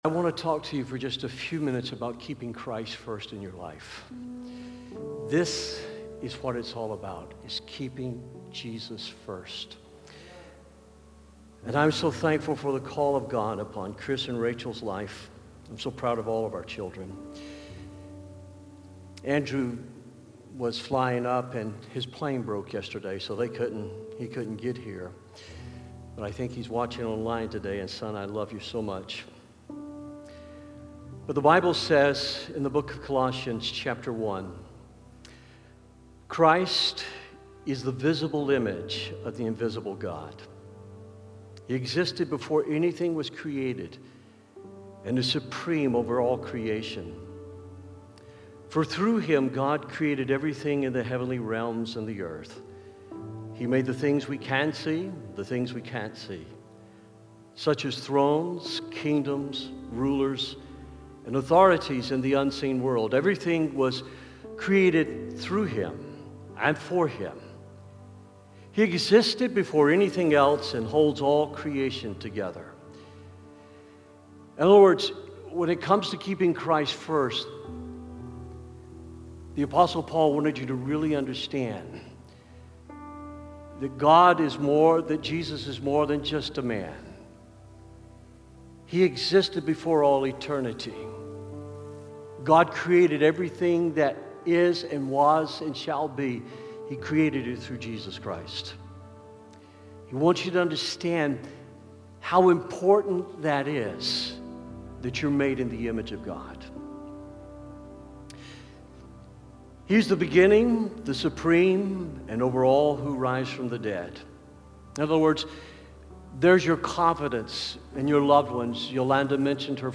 preaches about five things with which to keep Jesus first in our lives.